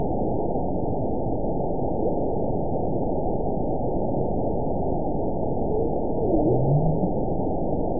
event 921838 date 12/19/24 time 20:17:41 GMT (11 months, 2 weeks ago) score 9.32 location TSS-AB02 detected by nrw target species NRW annotations +NRW Spectrogram: Frequency (kHz) vs. Time (s) audio not available .wav